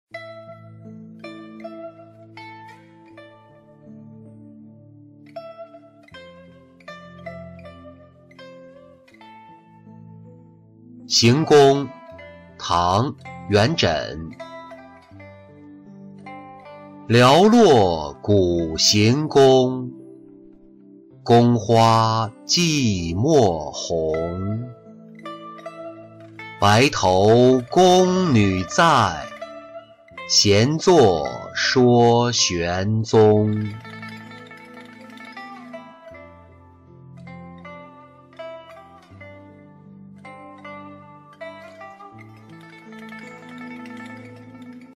行宫-音频朗读